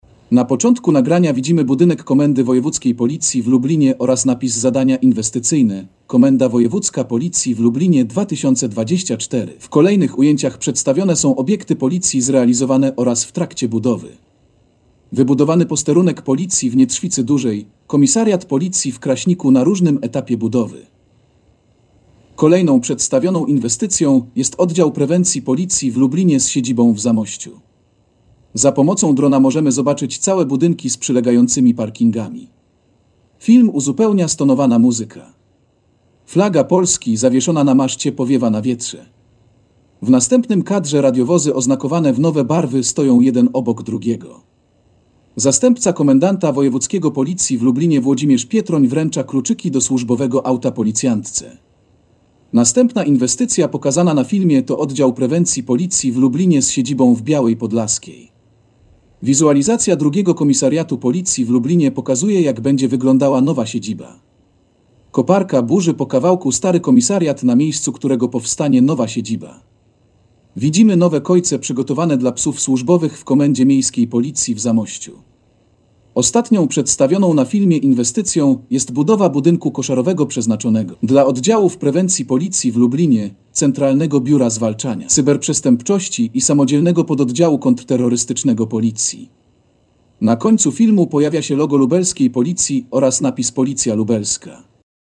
Nagranie audio Audiodeskrypcja filmu inwestycje w 2024 roku